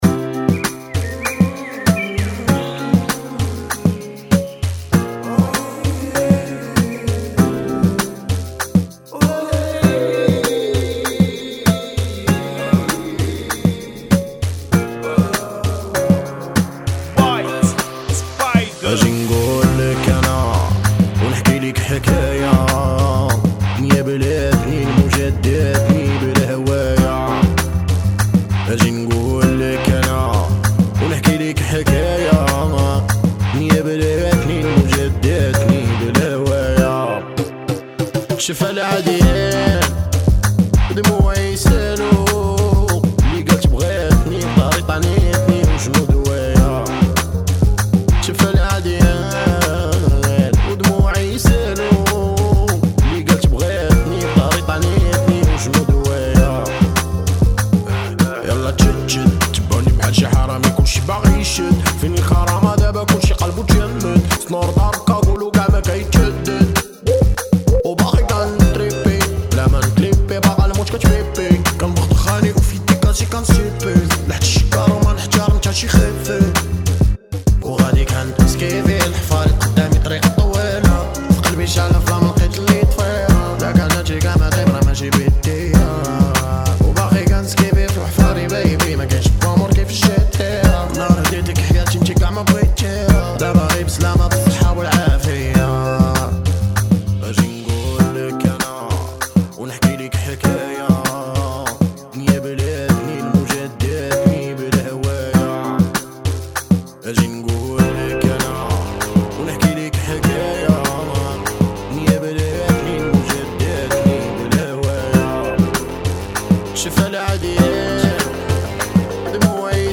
[ 98 Bpm ]